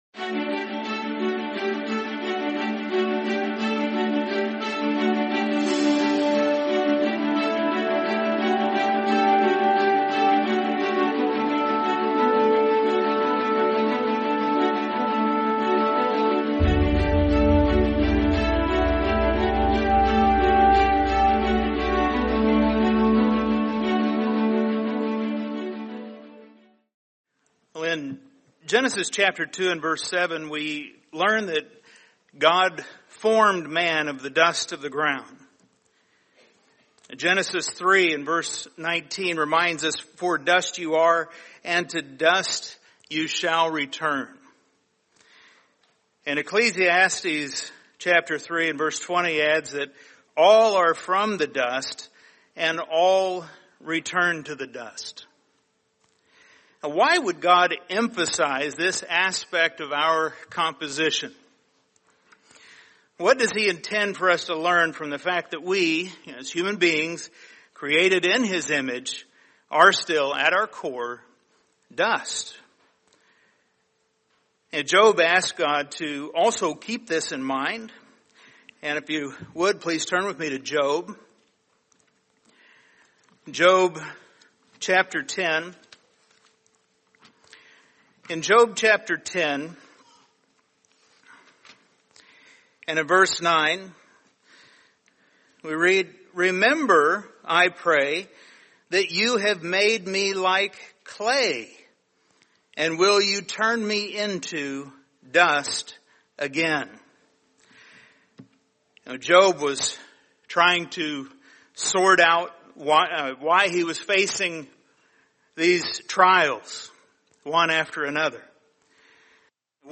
Sermon What It Means to Be Clay